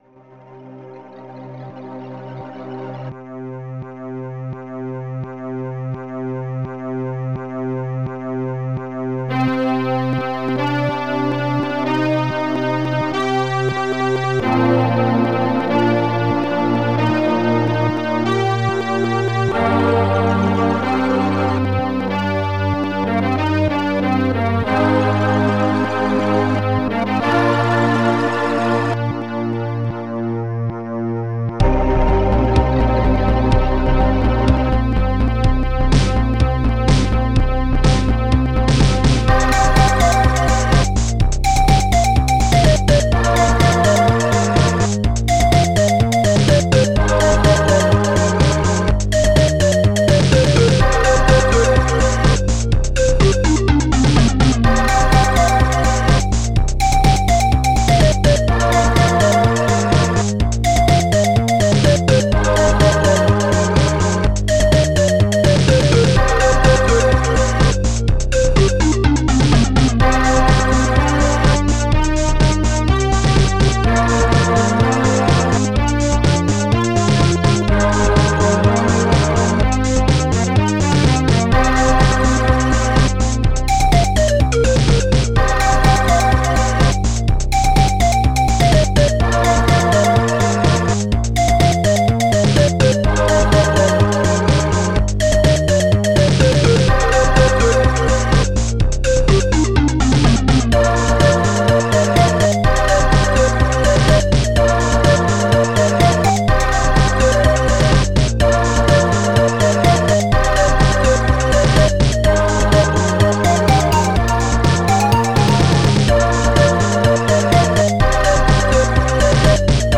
ST-09:PANMALLET1
ST-08:SYNTHBASS1
st-12:acidbd01
ST-08:SYNTHBRASS